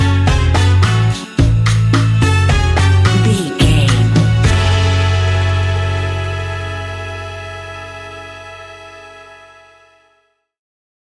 Aeolian/Minor
F#
steelpan
calypso music
drums
bass
brass
guitar